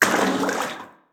WaterSplash_In_Short4.wav